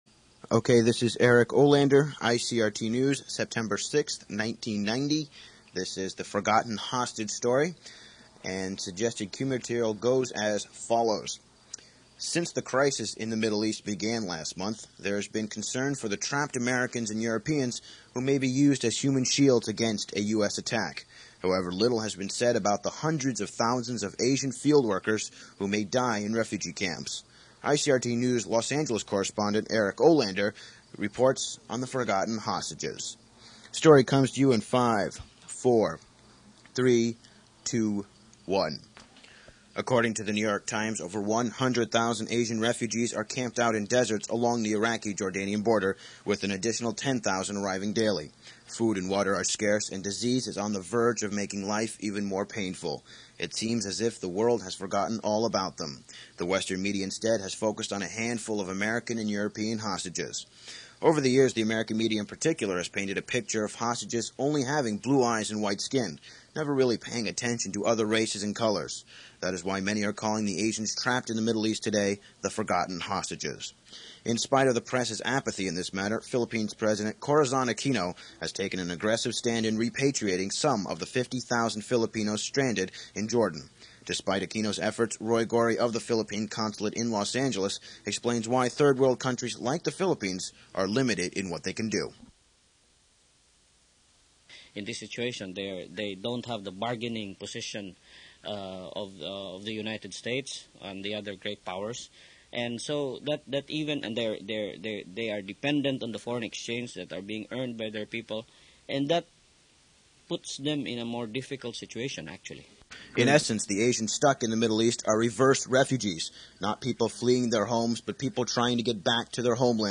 Later, I got the chance to anchor, produce and report from the US and Taiwan for the station.